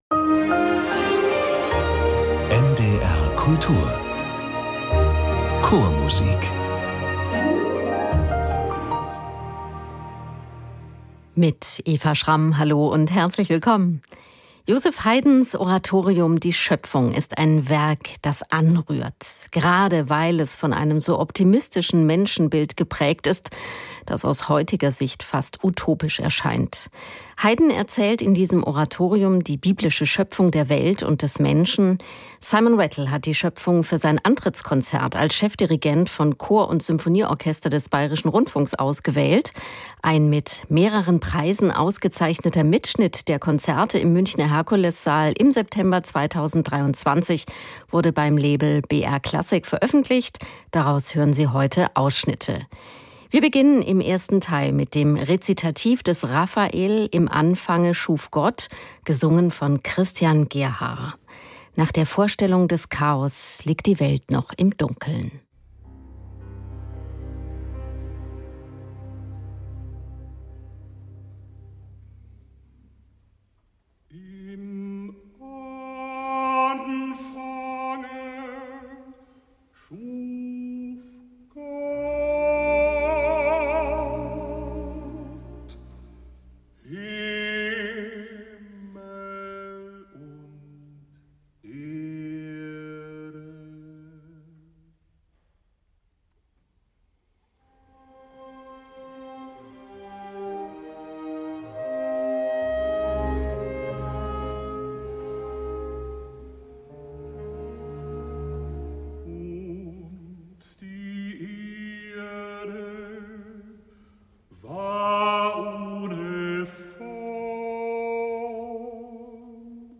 Preisgekrönter Mitschnitt des Antrittskonzerts von Simon Rattle als Chefdirigent von Chor und Symphonieorchester des BR.